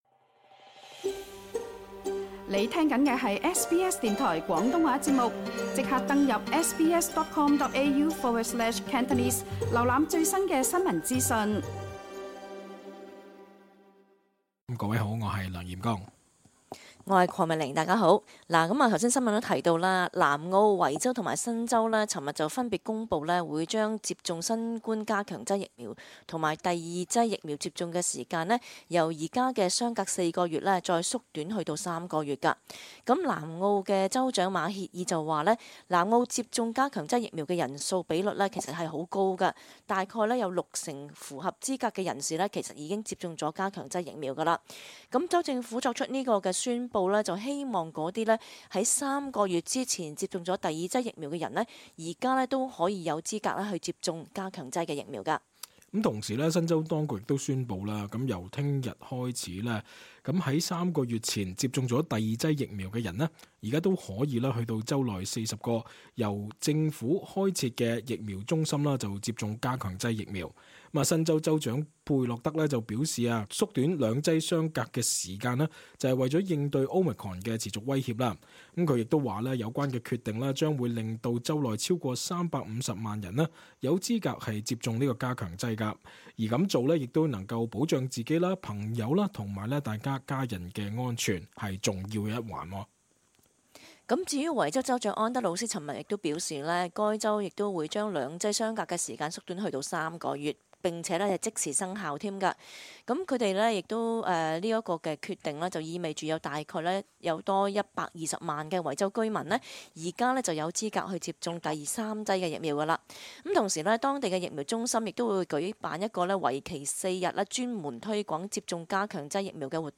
cantonese_talkback_jan_20_final_2upload.mp3